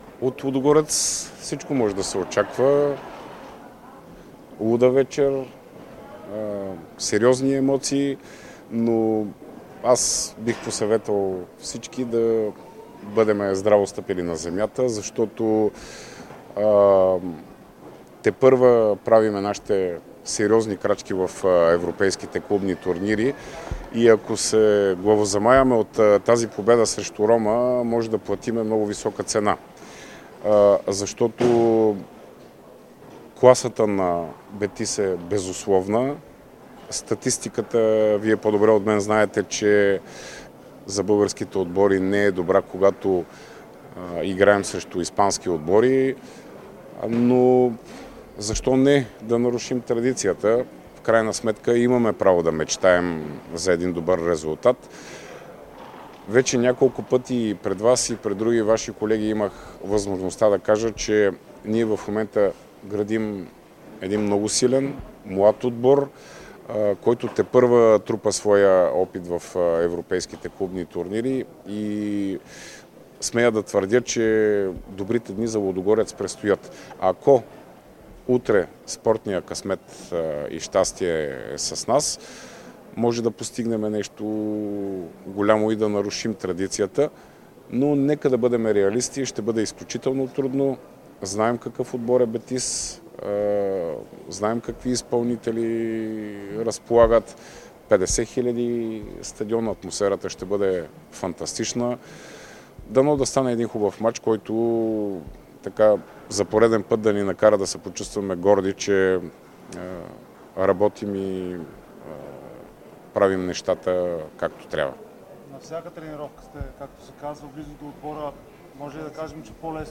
говори пред журналистите преди излитането на отбора за Испания